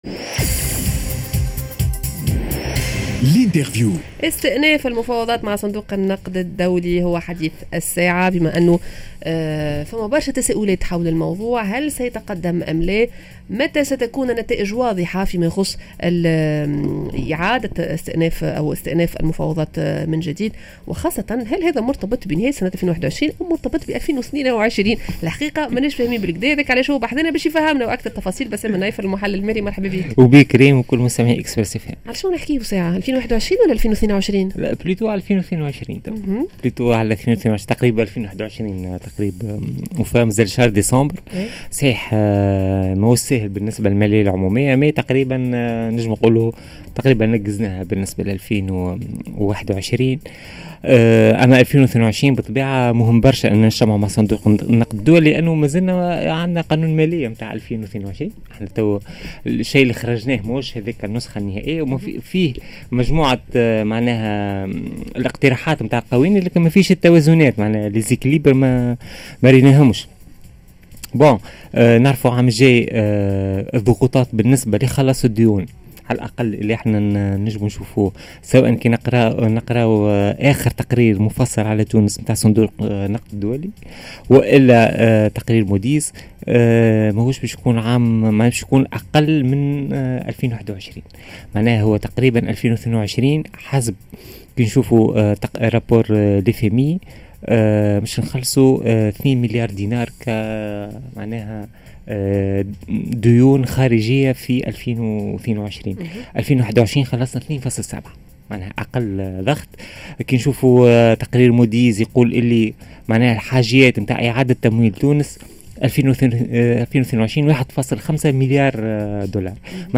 معانا المحلل المالي